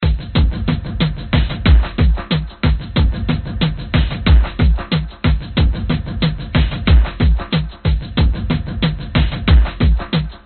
描述：我今天在Hammer上做的另一个Groovy loop......在民族合成器循环中可以很好地工作。
Tag: 电子 世界